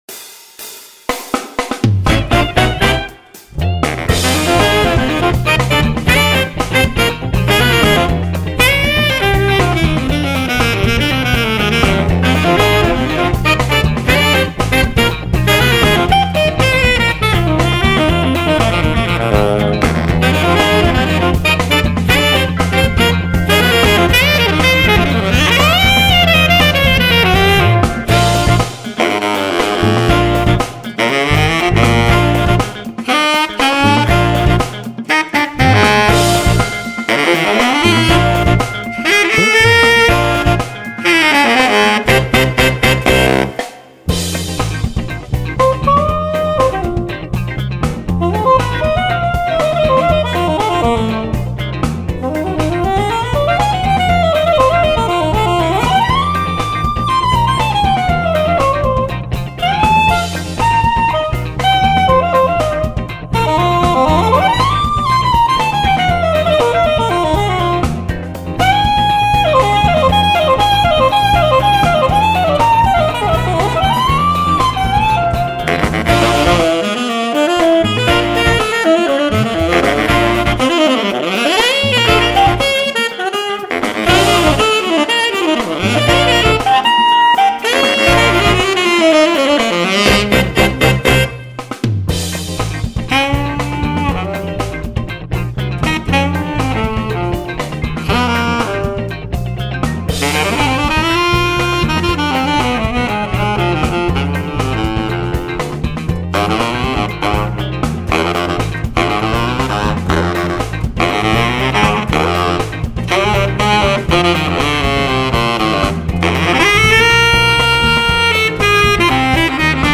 Features and Tone: Moderately dark, thick and contoured